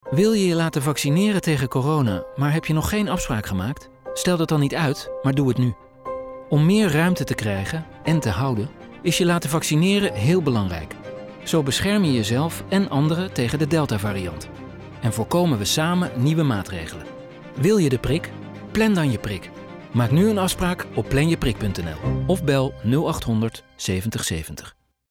radio ad for the Dutch audience urges everyone not to wait, but to schedule an appointment if you want a COVID-19 vaccination.